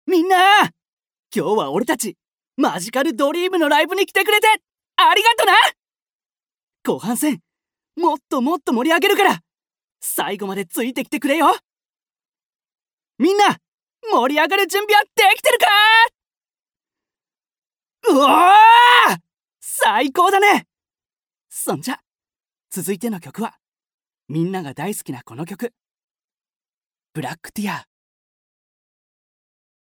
アトリエピーチのサンプルボイス一覧および紹介